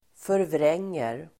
Uttal: [förvr'eng:er]